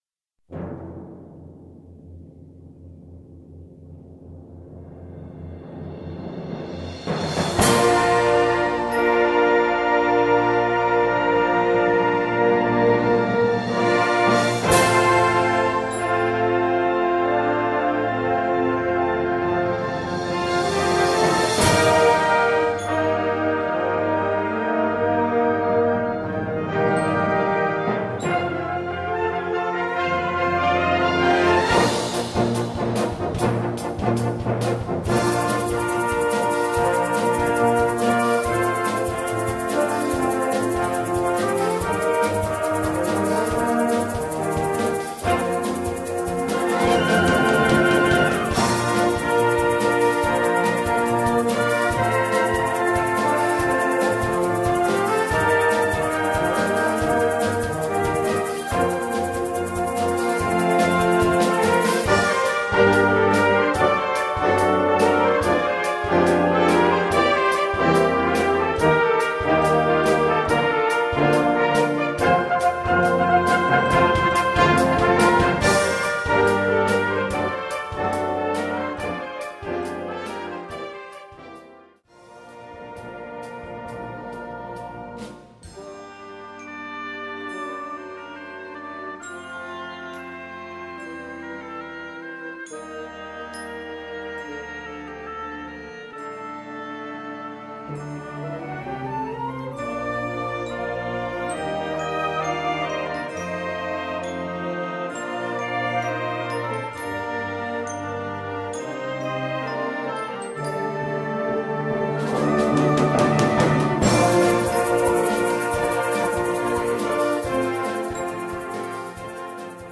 Gattung: Konzertmusik
Besetzung: Blasorchester